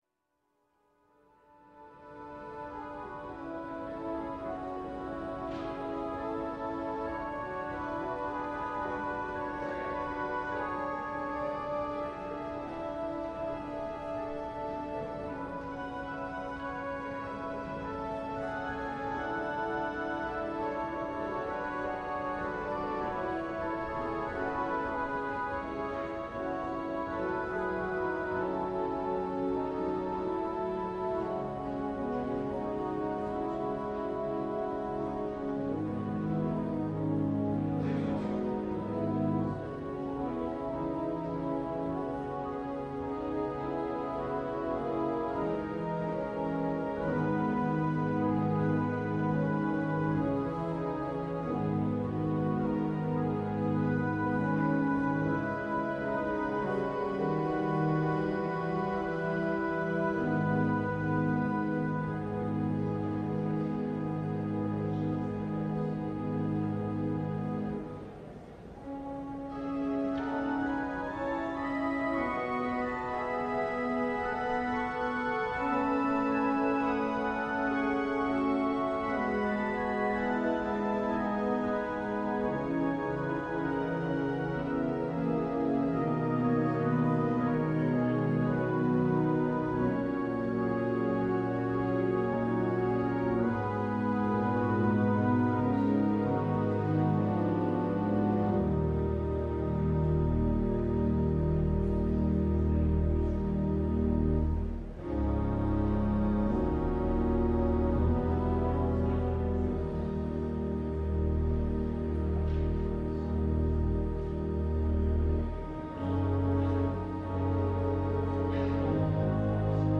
LIVE Morning Service - The Word in the Windows: The Transfiguration